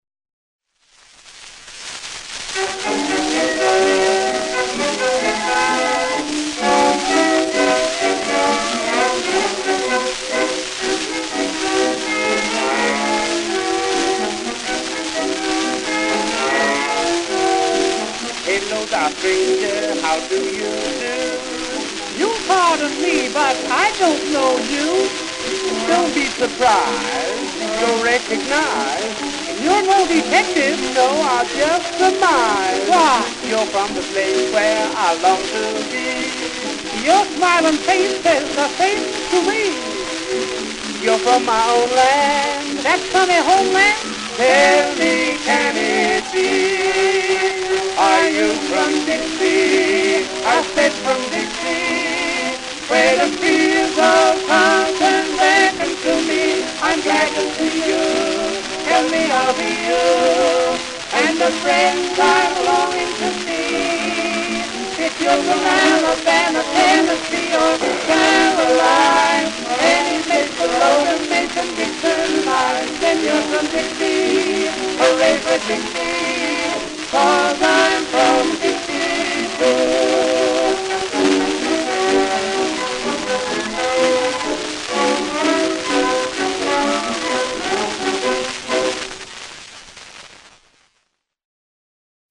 Tenor Duet (transfer courtesy of Internet Archive).